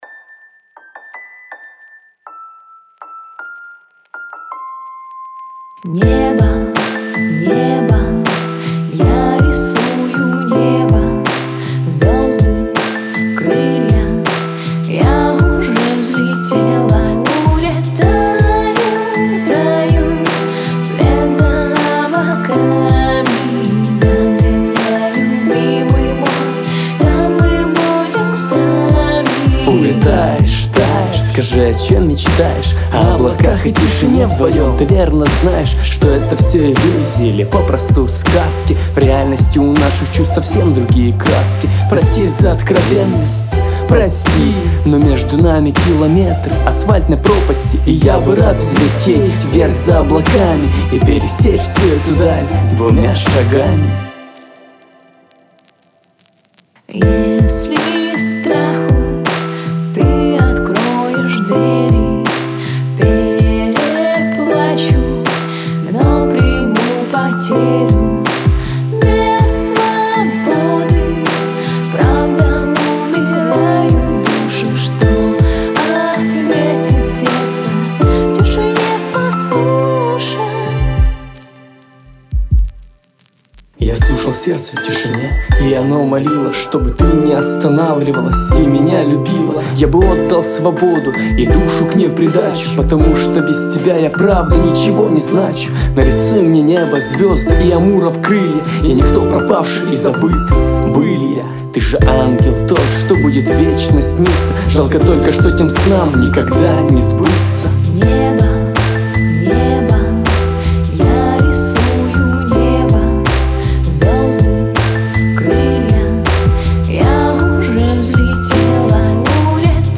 замечательному, нежному вокалу